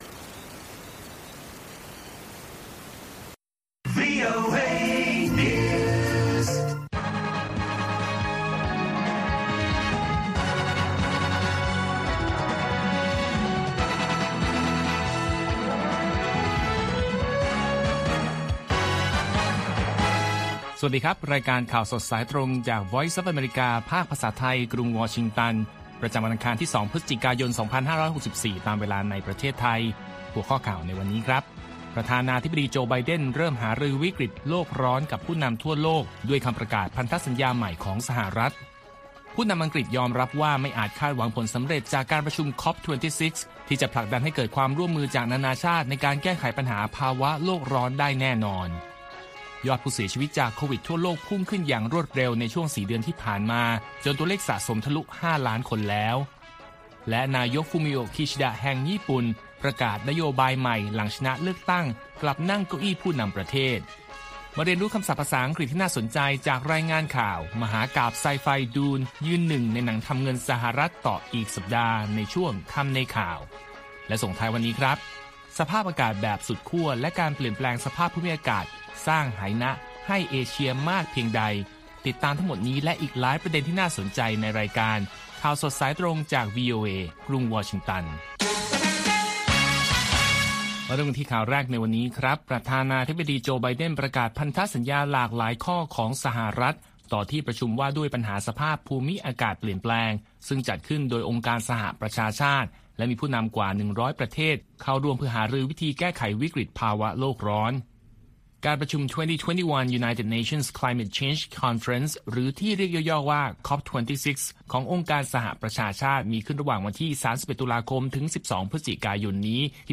ข่าวสดสายตรงจากวีโอเอ ภาคภาษาไทย ประจำวันอังคารที่ 2 พฤศจิกายน 2564 ตามเวลาประเทศไทย